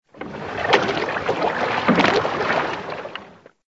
SZ_DD_waterlap.ogg